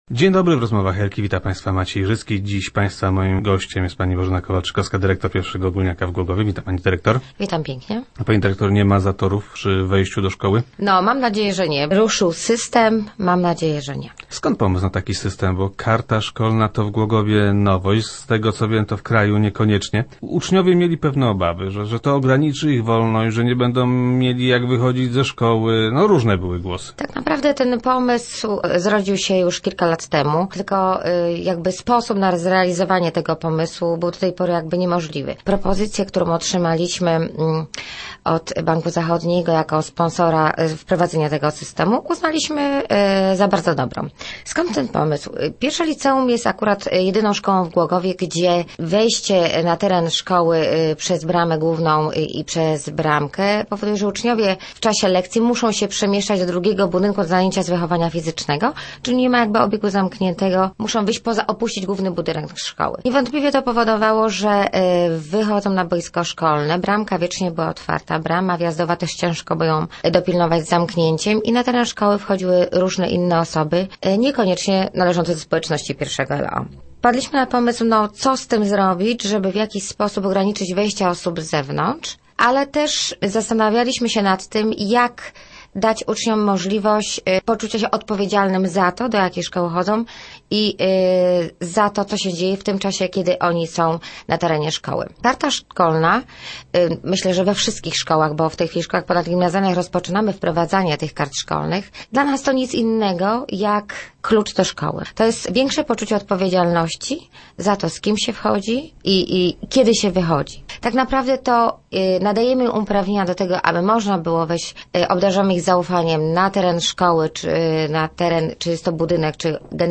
- Zdziwiona jestem tym poruszeniem – mówiła we wtorkowych Rozmowach Elki.